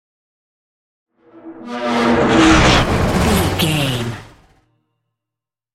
Sci fi vehicle whoosh large
Sound Effects
dark
futuristic
whoosh